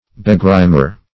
\Be*grim"er\
begrimer.mp3